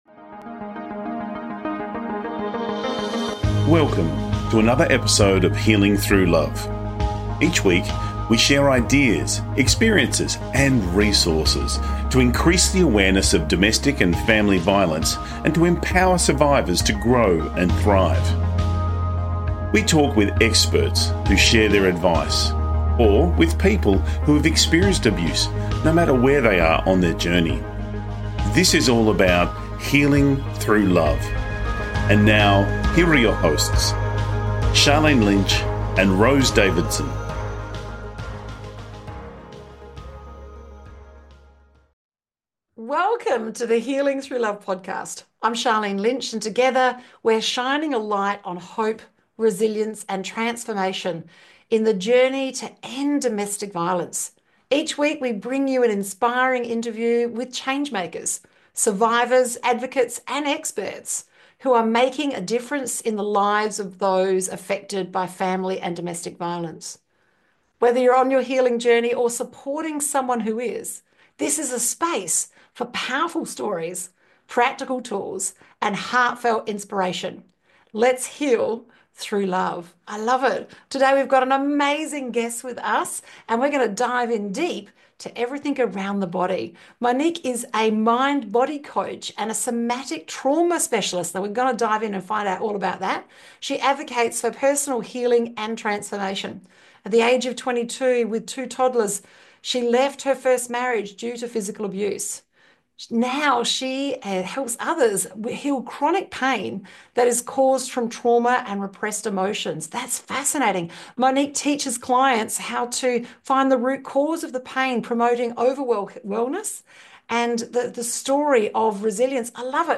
Key Points from the Interview: How to initiate the healing process after leaving a DV relationship, including practical somatic techniques.